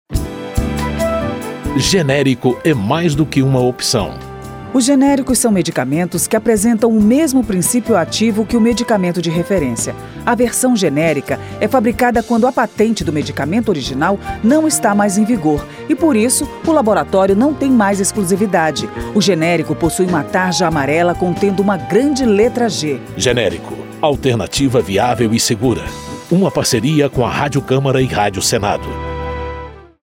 spot-01-parceiras-o-que-generico.mp3